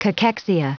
Prononciation du mot cachexia en anglais (fichier audio)
Prononciation du mot : cachexia